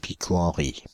Ääntäminen
France (Île-de-France): IPA: /pi.kɔ.ɑ̃.ʁi/